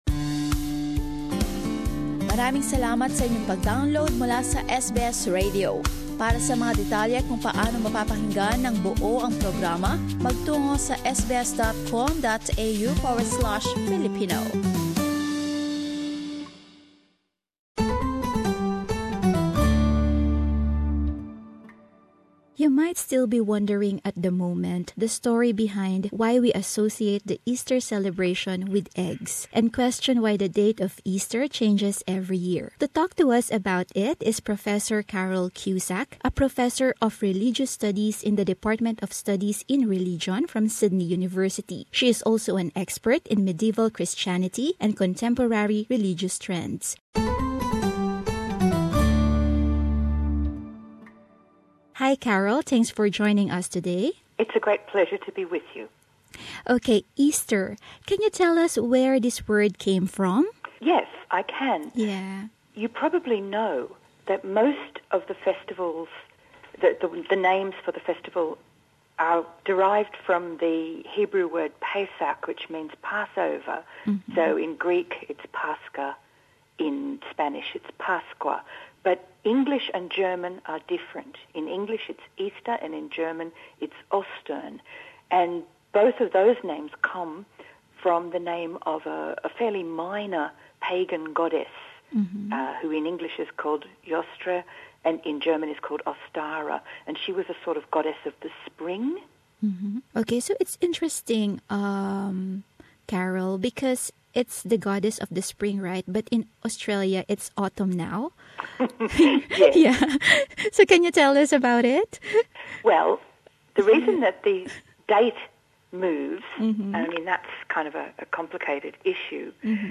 Alamin ito sa panayam